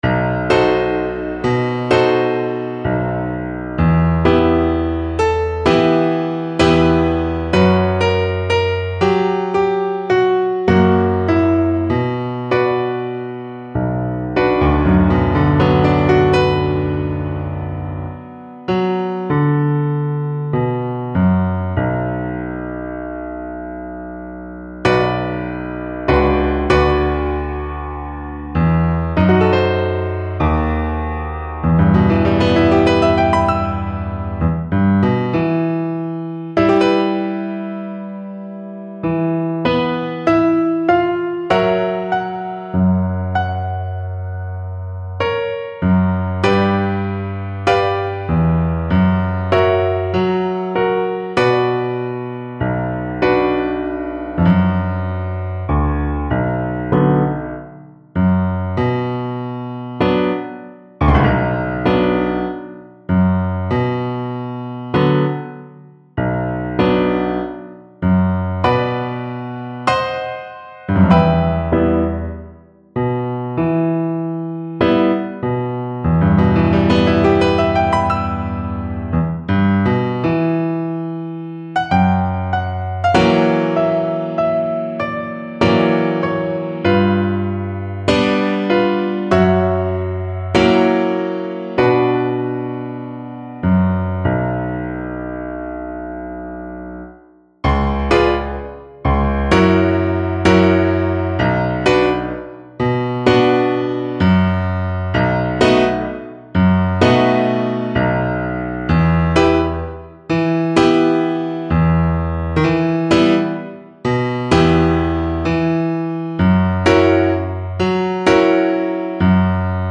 Cello version
Moderato =c.100
4/4 (View more 4/4 Music)
Eastern European for Cello